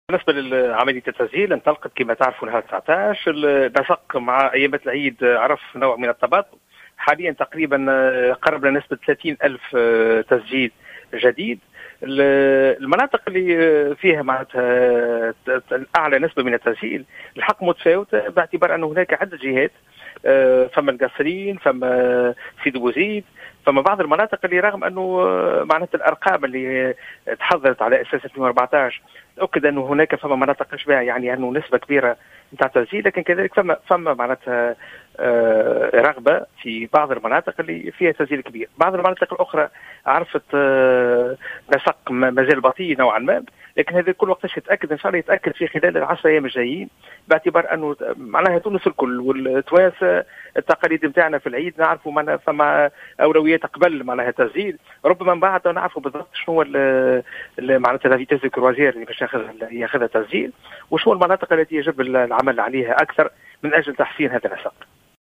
وأضاف في تصريح لـ"الجوهرة أف أم" اليوم أن عملية التسجيل شهدت نسقا بطيئا خلال أيام عيد الفطر، مشيرا أيضا إلى أن نسبة التسجيل تختلف من جهة إلى أخرى.